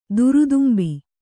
♪ durudumbi